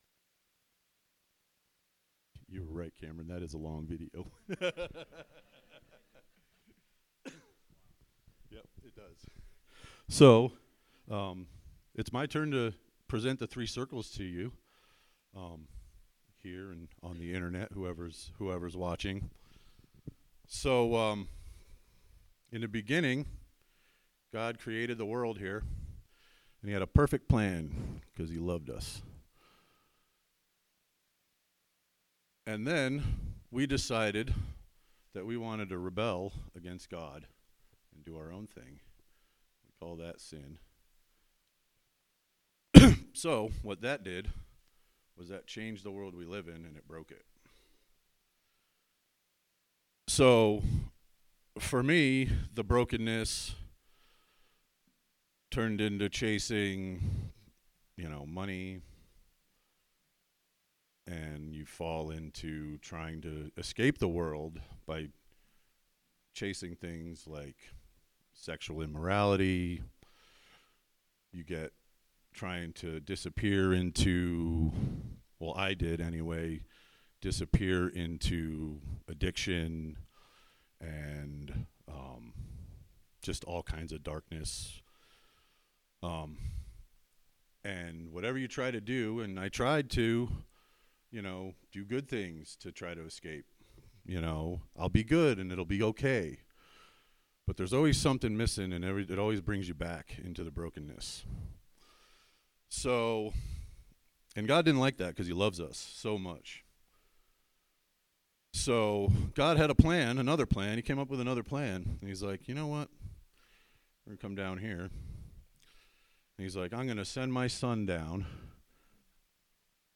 Sermons | Explore Church